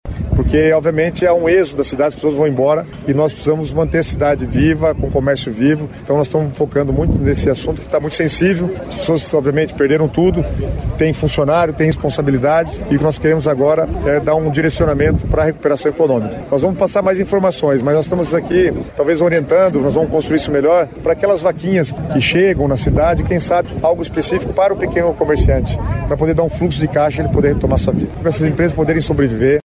Uma nova reunião com as secretarias da área econômica está prevista para o início da próxima semana, com o objetivo de consolidar um plano de recuperação abrangente para a reconstrução das empresas e preservação dos empregos, como destacou Guto Silva.